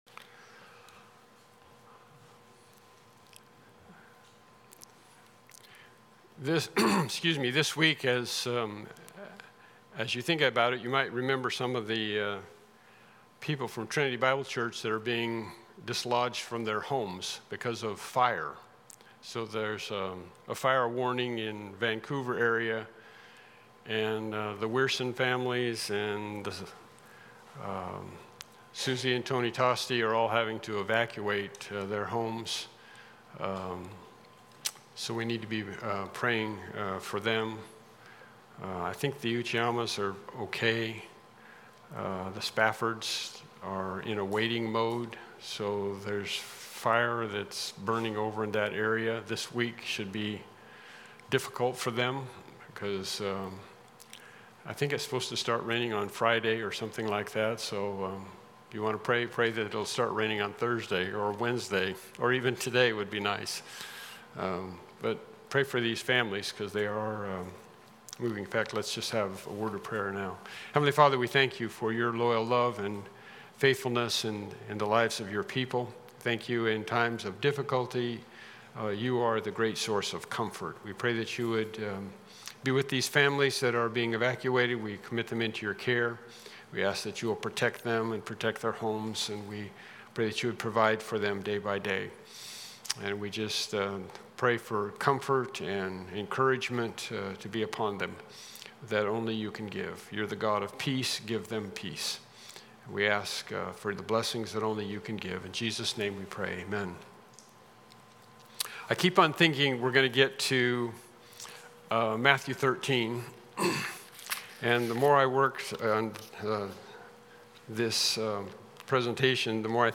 The Study of Things to Come Service Type: Evening Worship Service « The Parable of the Vineyard Lesson 8